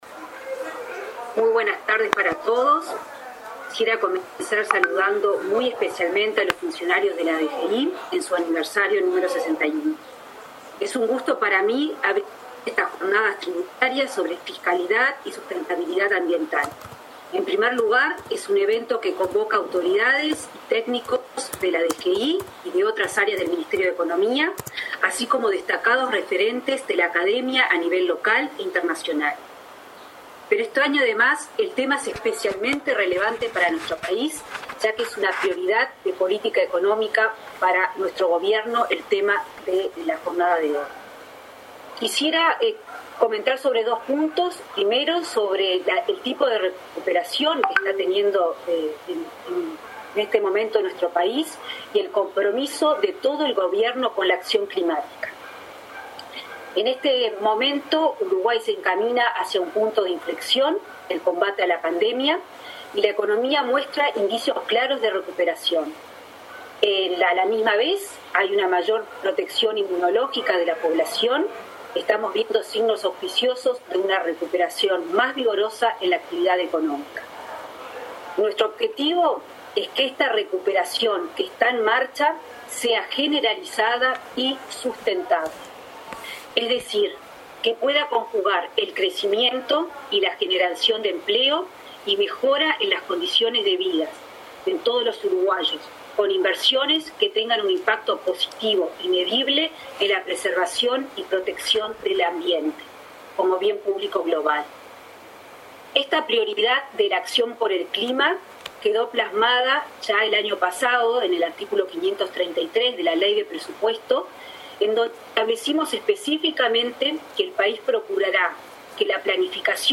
Palabras de la ministra de Economía, Azucena Arbeleche
La ministra de Economía, Azucena Arbeleche, participó el martes 30 de noviembre de la apertura de las jornadas tributarias que se realizan de forma